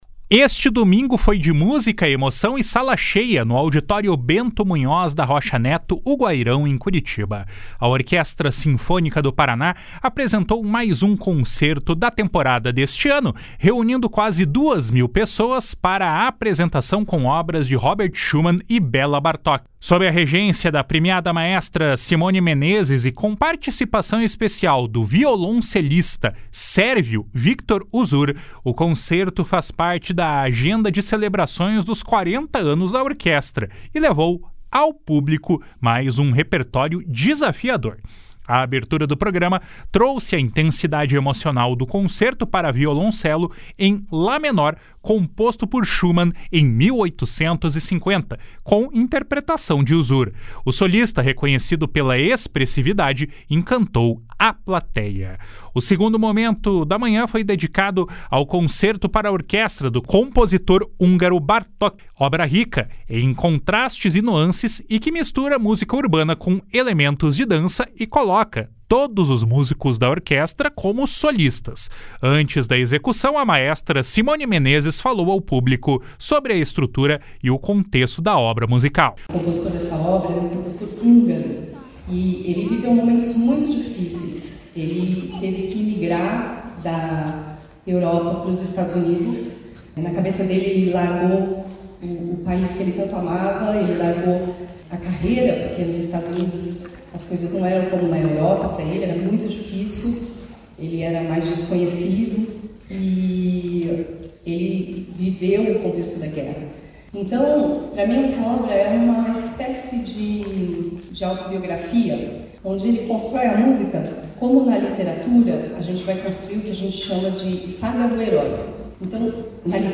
A Agência Estadual de Notícias lançou uma série de cinco reportagens especiais em comemoração aos 40 anos da Orquestra Sinfônica do Paraná.